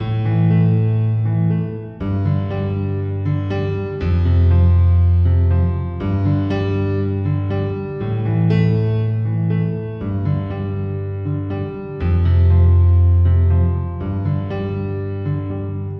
情感钢琴120bpm
Tag: 120 bpm RnB Loops Piano Loops 2.69 MB wav Key : G